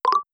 combobreak.wav